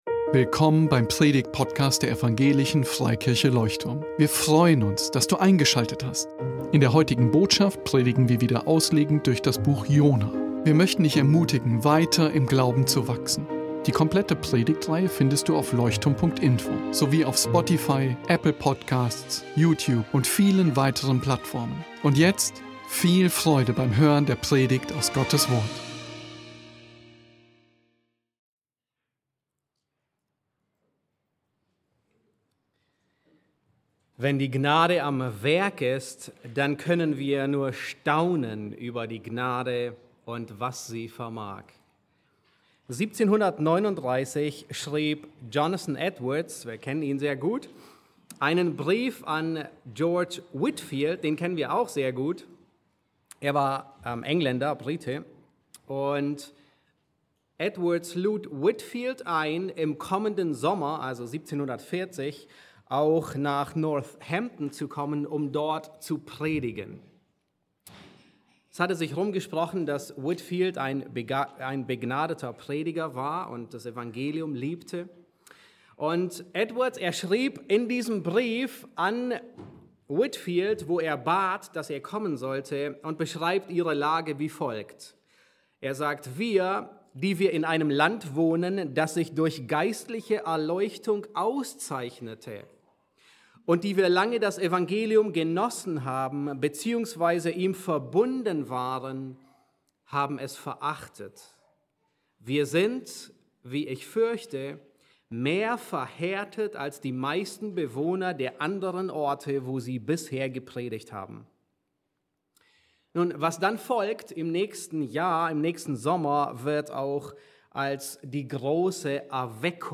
Leuchtturm Predigtpodcast Podcast